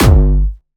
Jumpstyle Kick 7
4 G#1.wav